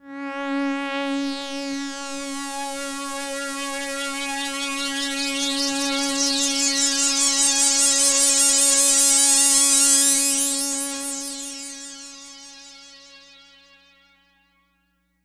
标签： CSharp5 MIDI音符-73 Korg的-Z1 合成器 单票据 多重采样
声道立体声